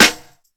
Snares
Boom Bap Snare.wav